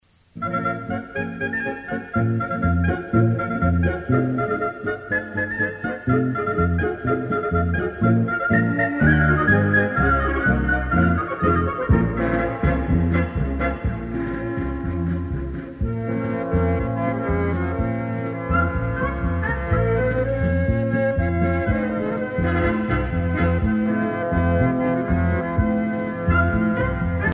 danse : paso musette
Pièce musicale éditée